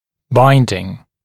[‘baɪndɪŋ][‘байндин]заедание, защемление дуги в пазе (состояние, когда созданные силы чрезмерны и сила трения в трех местах контакта дуги с пазом затрудняет или не позволяет какое-либо движение)